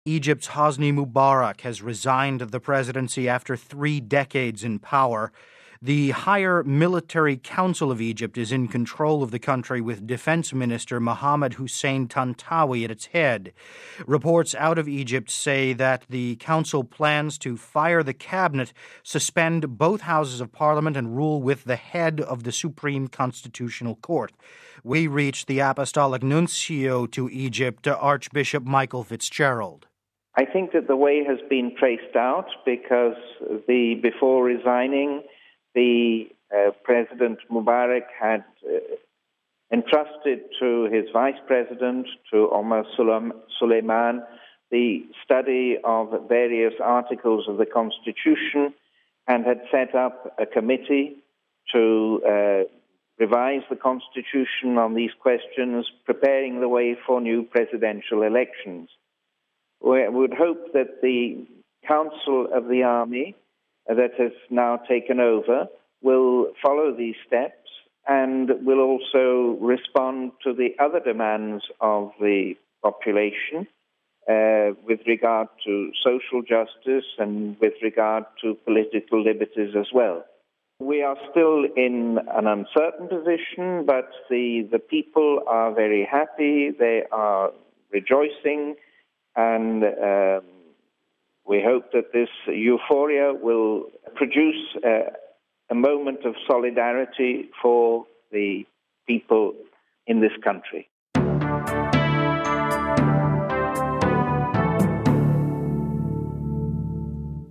Listen to Archbishop Fitzgerald's remarks: RealAudio